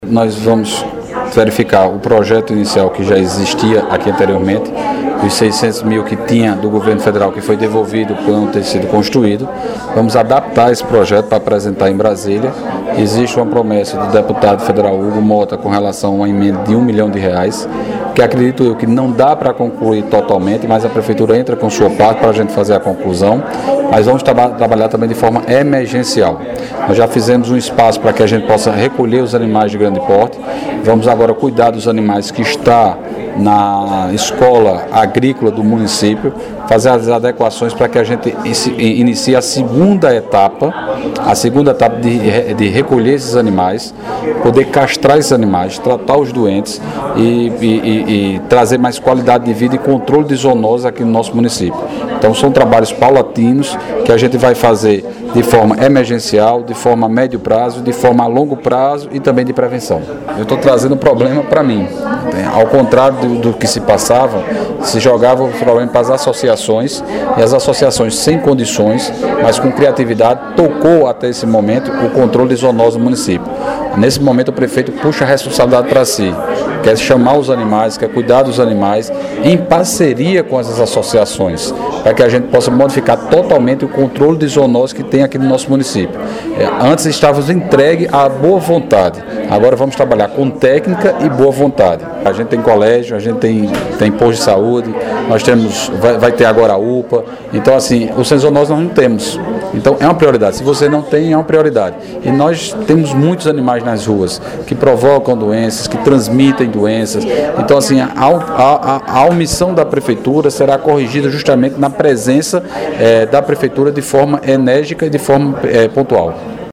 Fala do prefeito Dinaldinho Wanderley